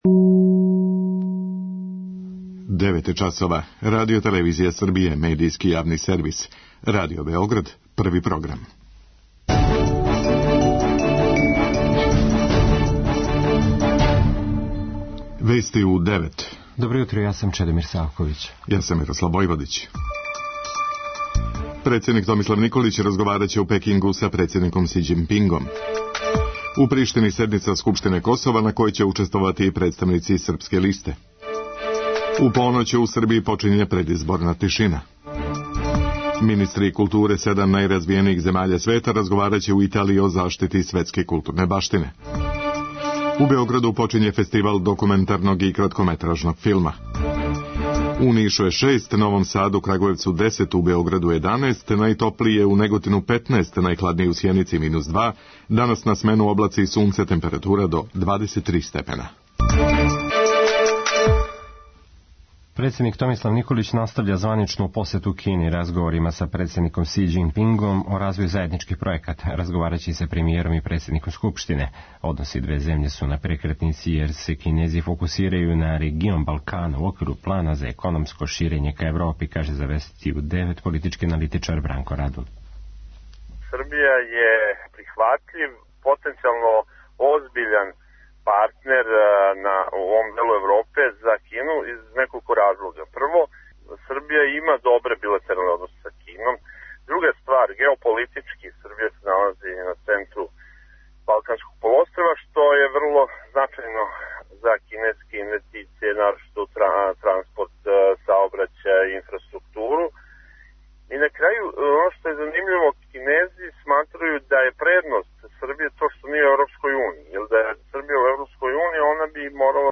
преузми : 3.70 MB Вести у 9 Autor: разни аутори Преглед најважнијиx информација из земље из света.